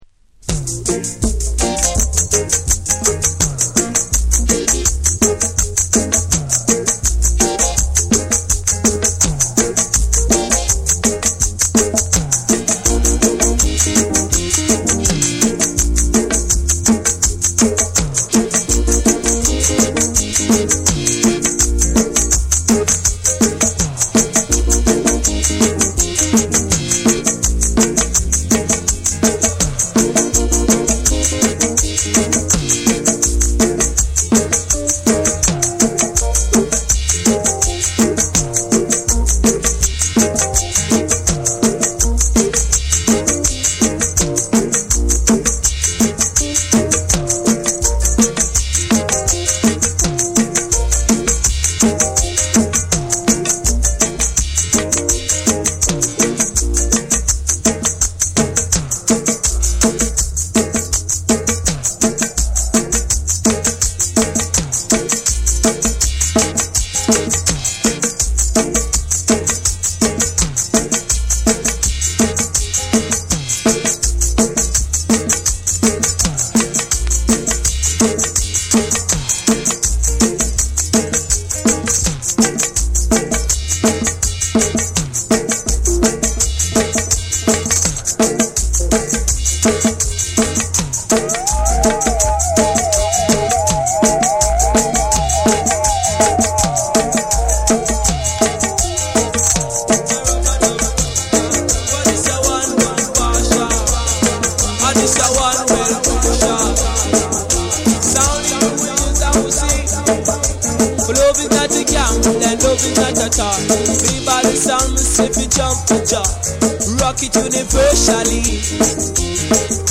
ミニマルで奥行きあるプロダクションと、ルーツ〜ダンスホール前夜の空気をまとった独特の世界観は今聴いてもフレッシュ！